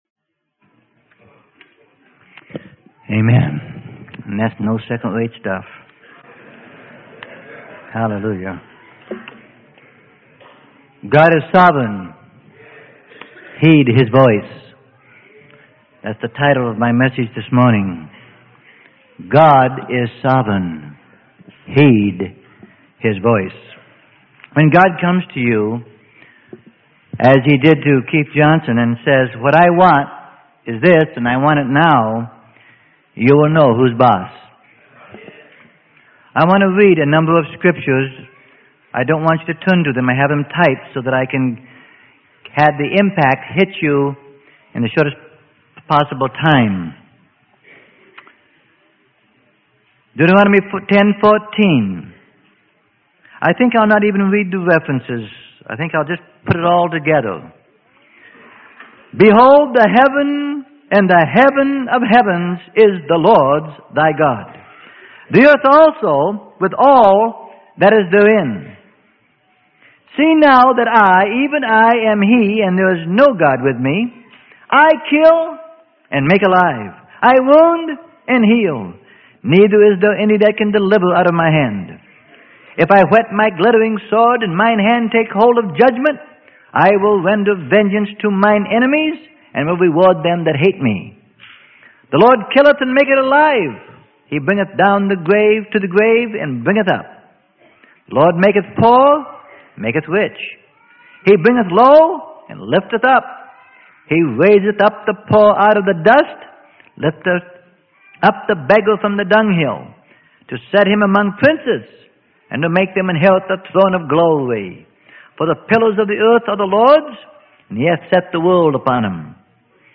Sermon: God is Sovereign - Heed His Voice - Freely Given Online Library